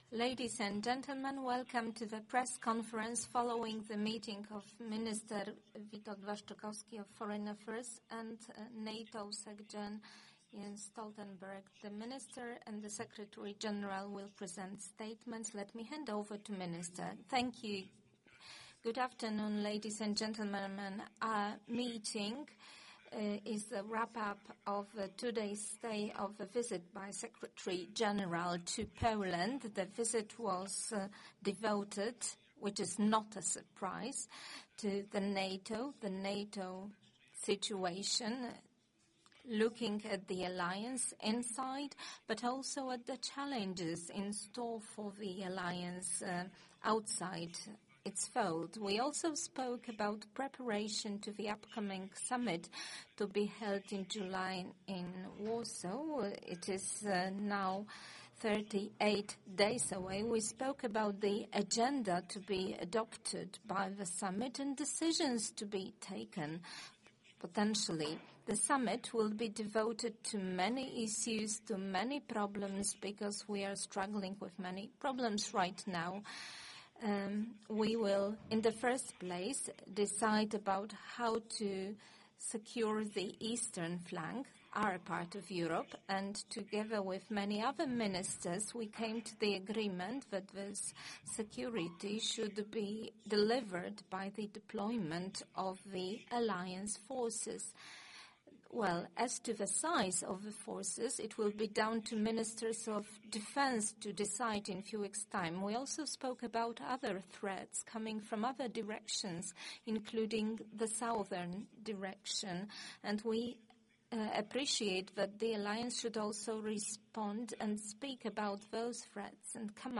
NATO Secretary General previews Warsaw Summit in speech at Warsaw University